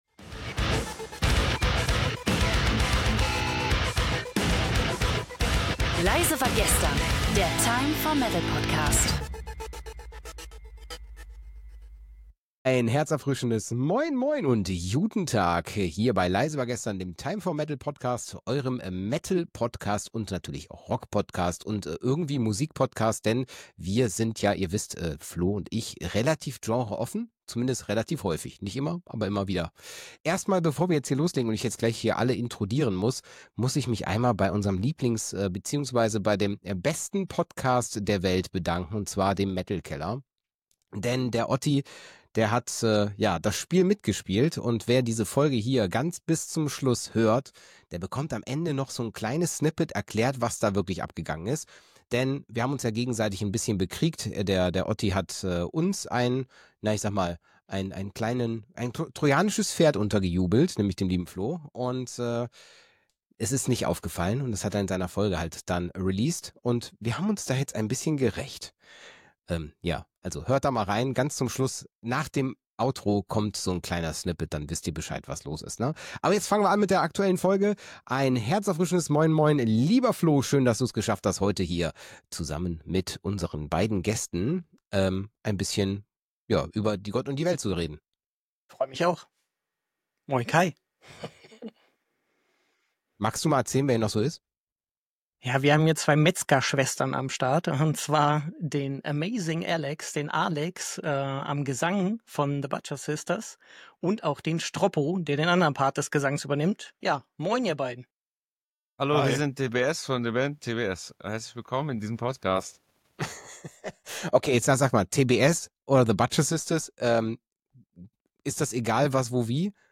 Noch nie war eine Folge so voller verrückter Ideen, spontaner Freestyles und abenteuerlicher Festivalpläne!
Freu dich auf jede Menge Lachflashs, abgedrehte Musikideen, wilde Live-Geschichten und unvergessliche Musikwünsche.